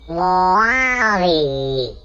Download Wall e sound effect for free.